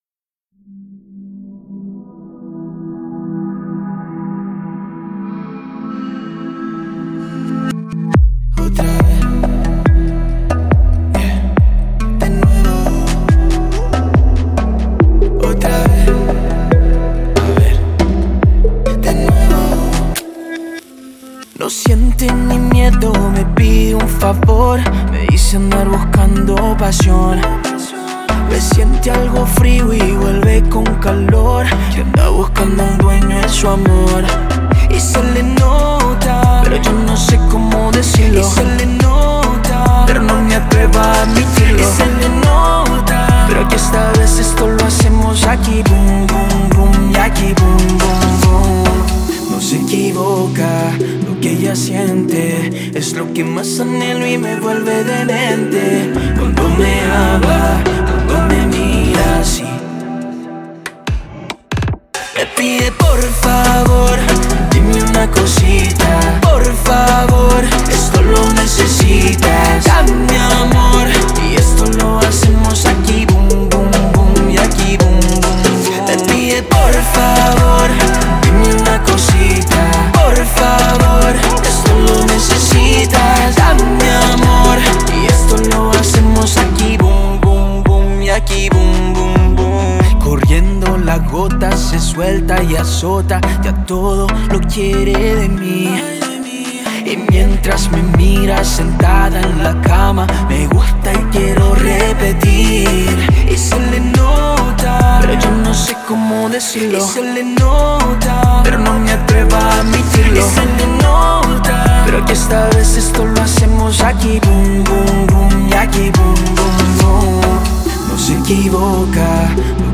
Latin
singer/guitarist